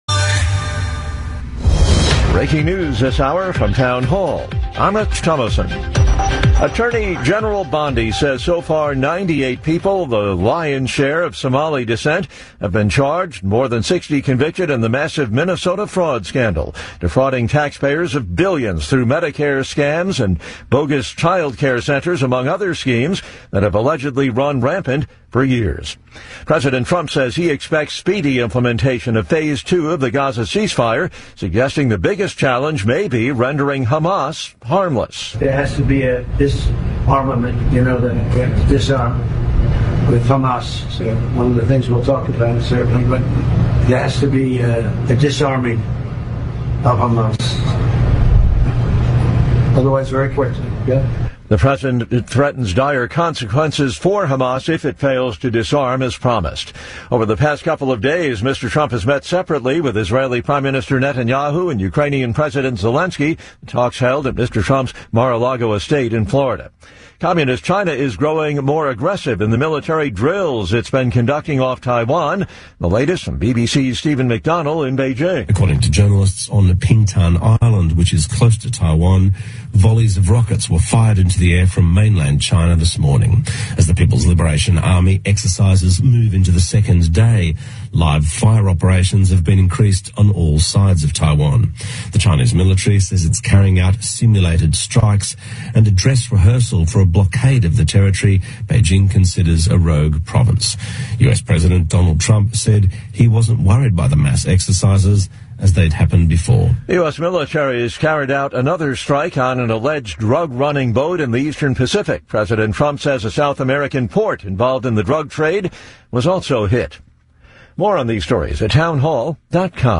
Guest host Nino Mangione tackles the latest controversy surrounding ICE protesters in Glen Burnie, where demonstrators defending illegal immigrants ramming into ICE vehicles. Nino also weighs in on the newly freed Kilmar Abregio Garcia taking to TikTok.